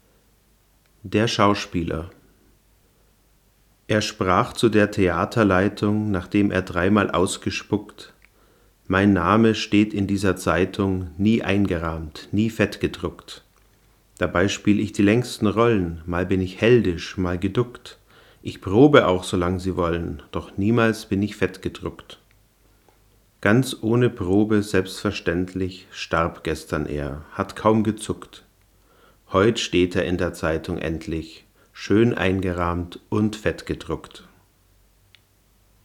Ich habe jetzt noch eine weitere Reihe gemacht, diesmal mit dem Rode NTG-2 aus ebenfalls ca. 60 cm Abstand.
Mikrofon direkt am Mic-Eingang des Camcorders Canon XA10
canon_xa10.mp3